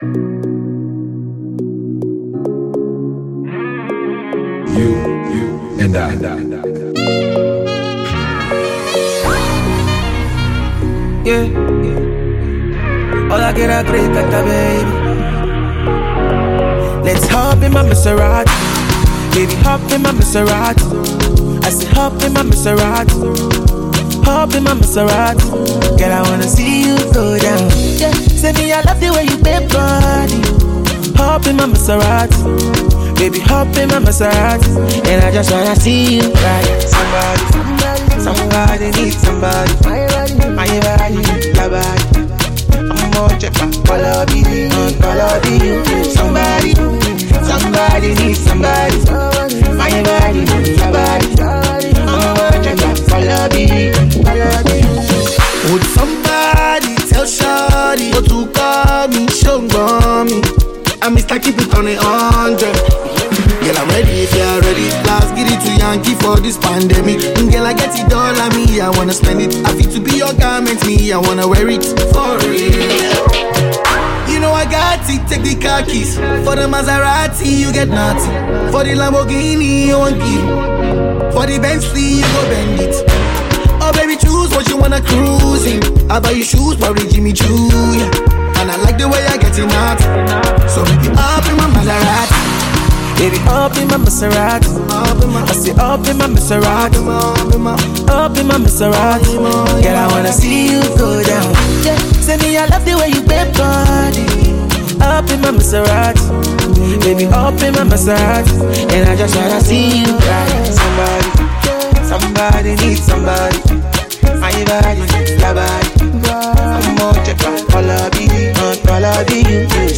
Afro-Pop and RnB singer/producer